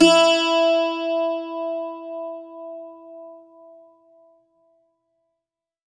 52-str07-bouz-e3.wav